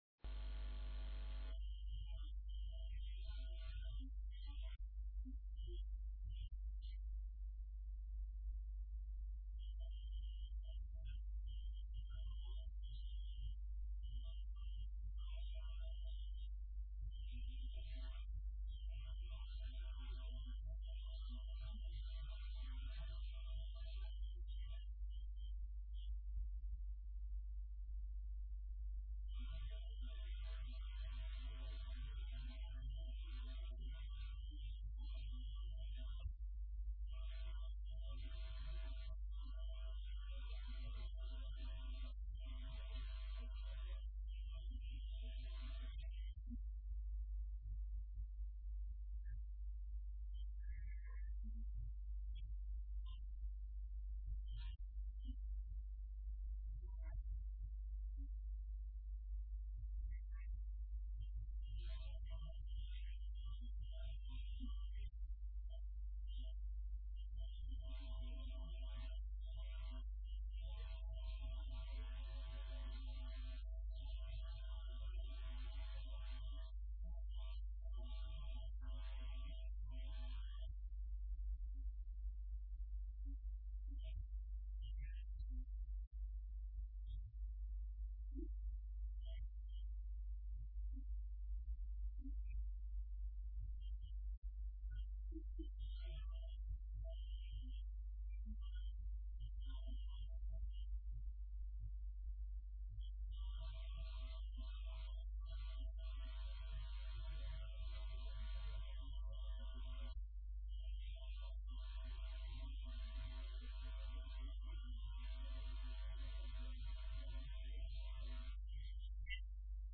تلاوتی از سوره مبارکه "اعراف" با صدای استاد منشاوی صوت - تسنیم
این تلاوت از مرحوم استاد محمد صدیق منشاوی در کشور لیبی ضبط شده است.